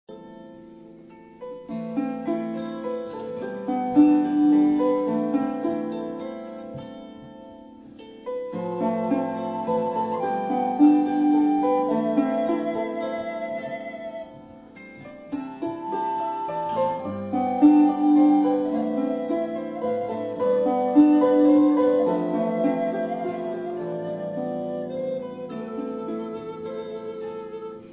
Harp
Alto Recorder
Guitar